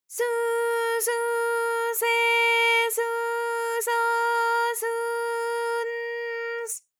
ALYS-DB-001-JPN - First Japanese UTAU vocal library of ALYS.
su_su_se_su_so_su_n_s.wav